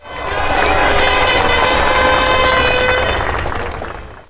1 channel
crowd_var4.mp3